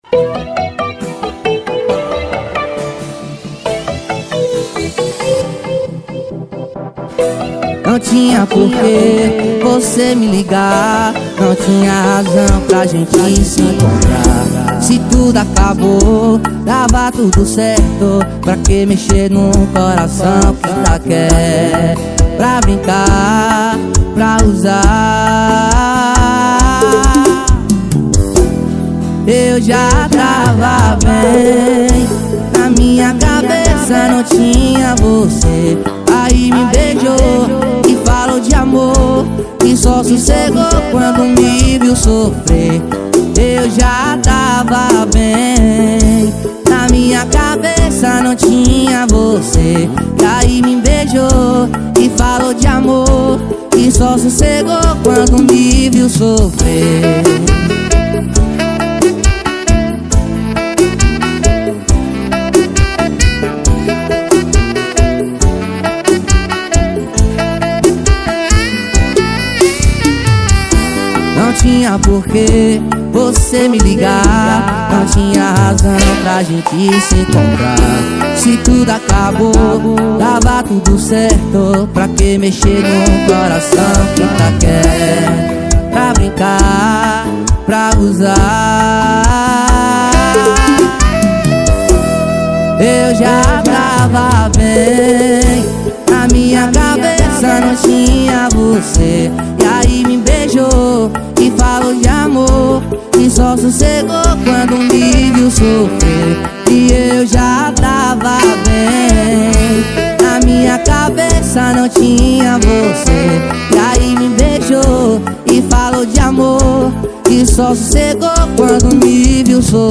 2024-07-07 23:27:11 Gênero: Sertanejo Views